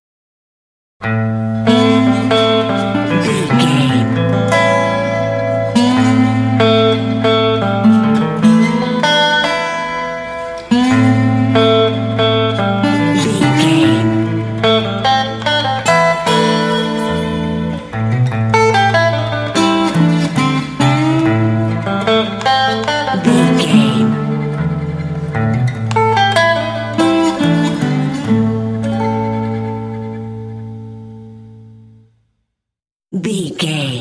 Aeolian/Minor
bouncy
groovy
acoustic guitar
americana